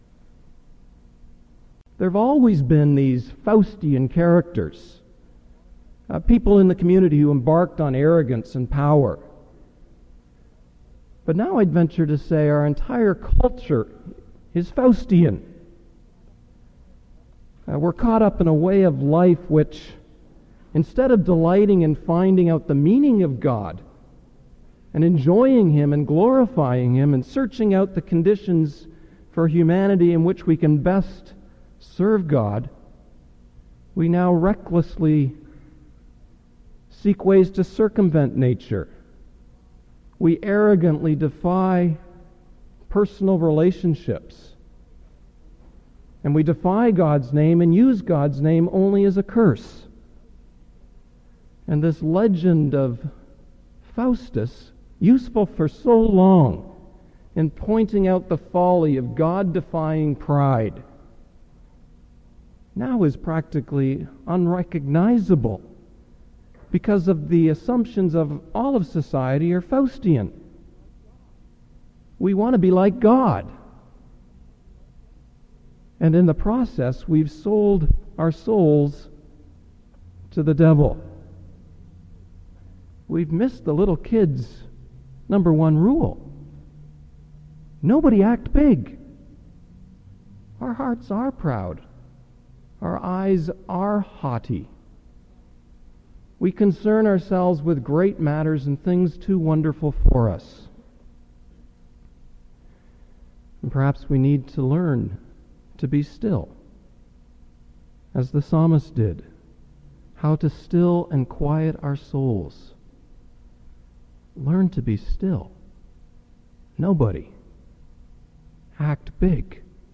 9979-first-8-min-not-recorded.mp3